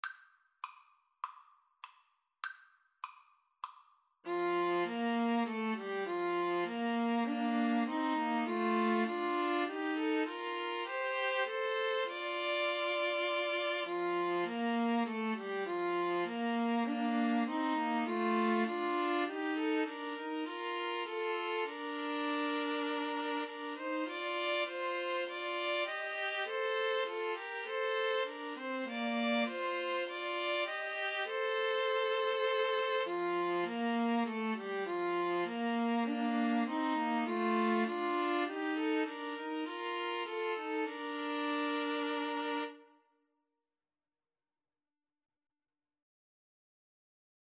Free Sheet music for String trio
Bb major (Sounding Pitch) (View more Bb major Music for String trio )
Classical (View more Classical String trio Music)